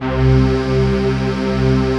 DM PAD2-10.wav